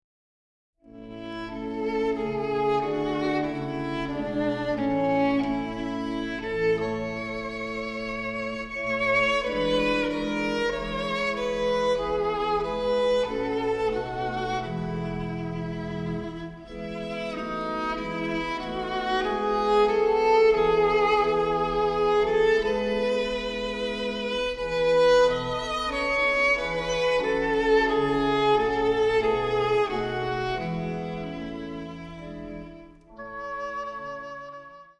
gemischter Chor, Orgel
• die Lieder werden mit besonderer Emotionalität vorgetragen